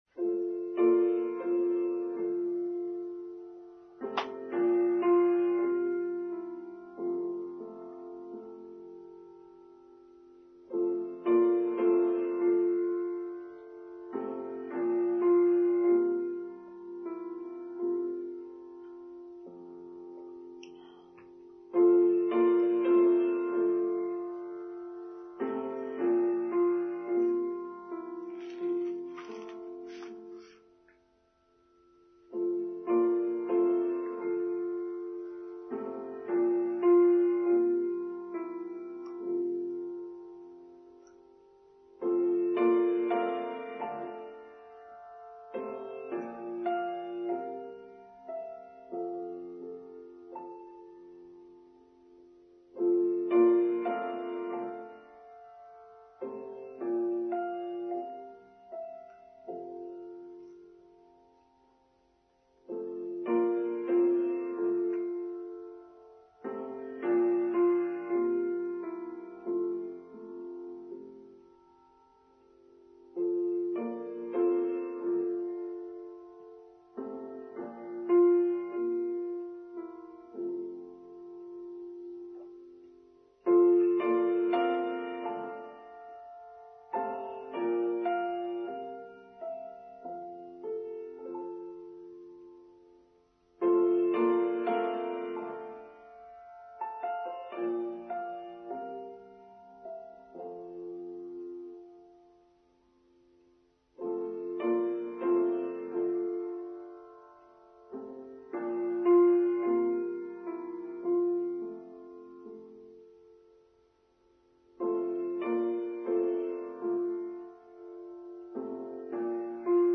Going with the Flow: Online service for Sunday 4th June 2023